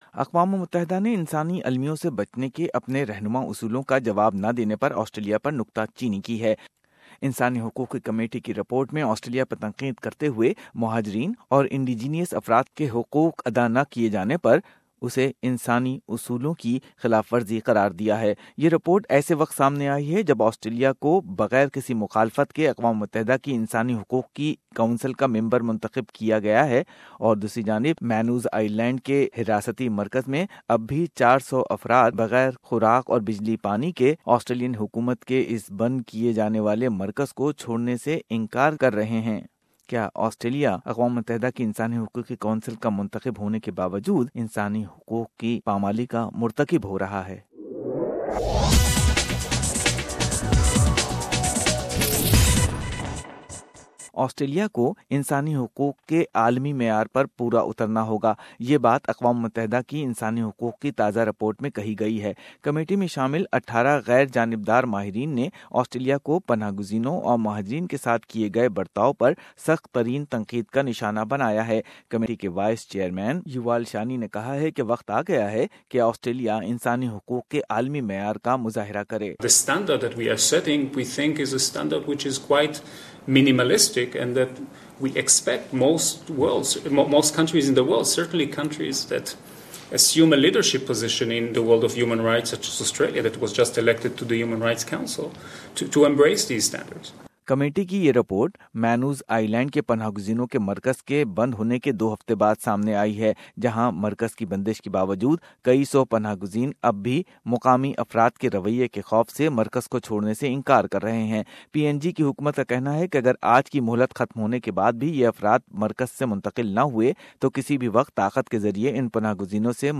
Pakistani refugee in Nauru sharing his experiences of fear of local community that does not want asylum seekers to relocate from closed detention centre. Australia has been condemned by the United Nations (UN) for its failure to respond appropriately to U-N humanitarian guidance.